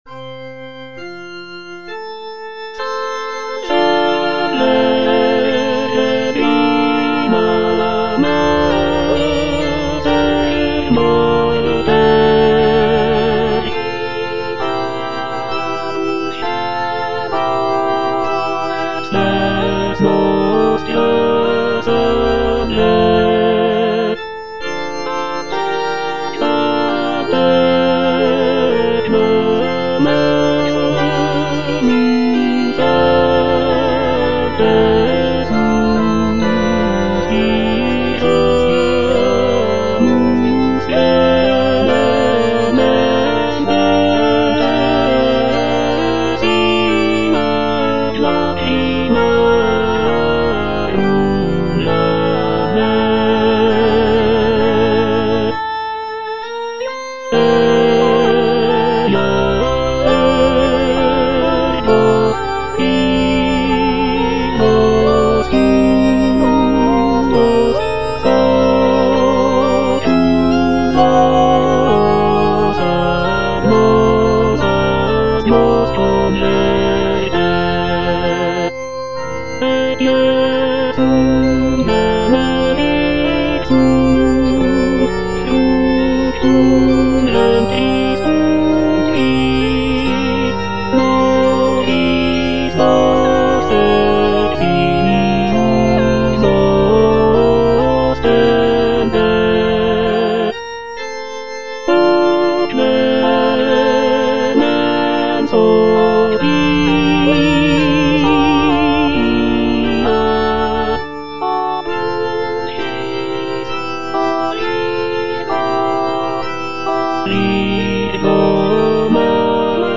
Tenor (Emphasised voice and other voices)
choral work